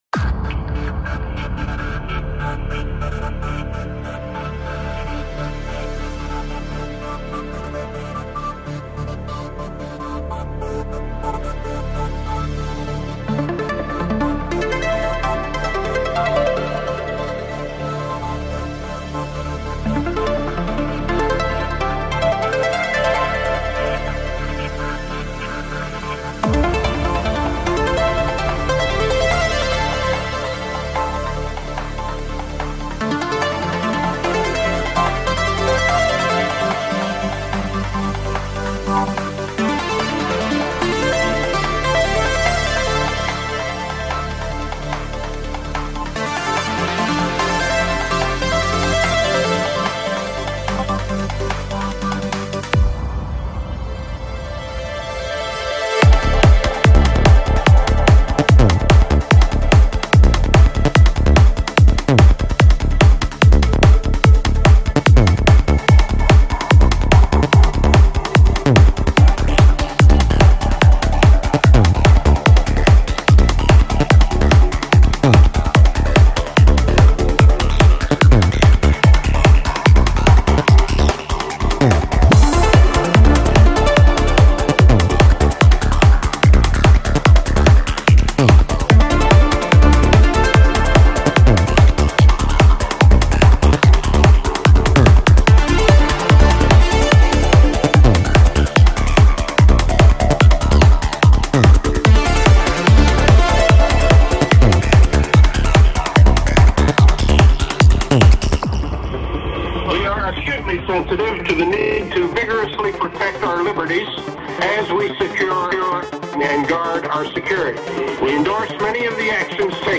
música electrónica
Goa trance